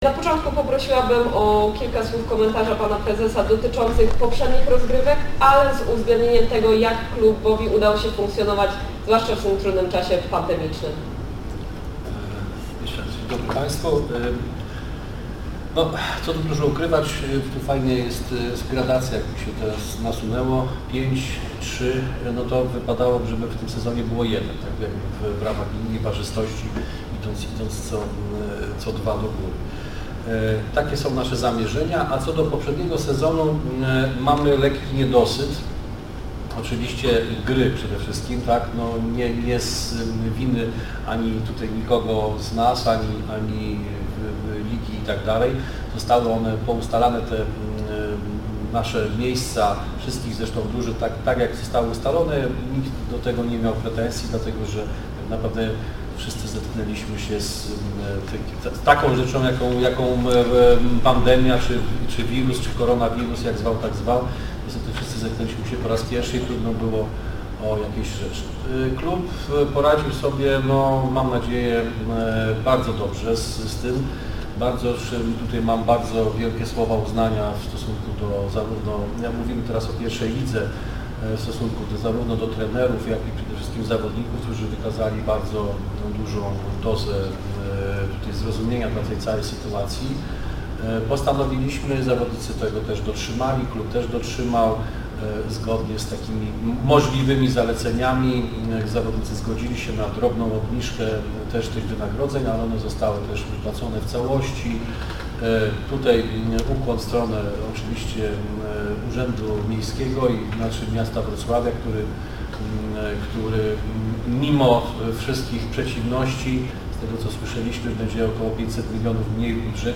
ZAPIS KONFERENCJI PRASOWEJ PRZED SEZONEM 2020/21 - WKK - Wrocławski Klub Koszykówki
konferencja-prasowa-WKK-WROCŁAW.mp3